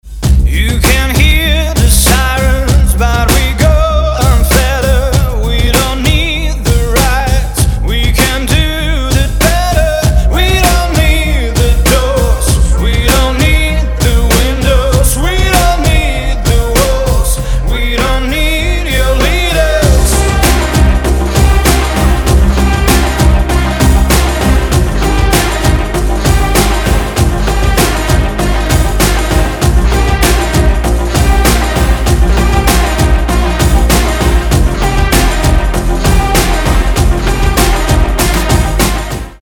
• Качество: 320, Stereo
indie rock